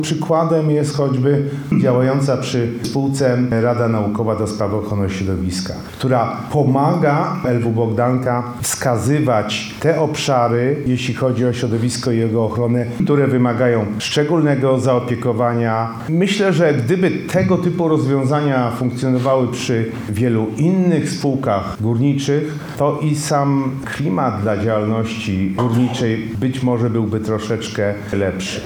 Na temat Lubelskiego Węgla Bogdanka w kontekście nowych uwarunkowań środowiskowych rozmawiano w Lublinie. Okazją do tych rozmów było seminarium zorganizowane przez UMCS.
Uczestniczący w wydarzeniu wiceminister klimatu i środowiska Krzysztof Galos przyznał, że Bogdanka dba o kwestie zrównoważonego rozwoju, uwzględniając ochronę środowiska.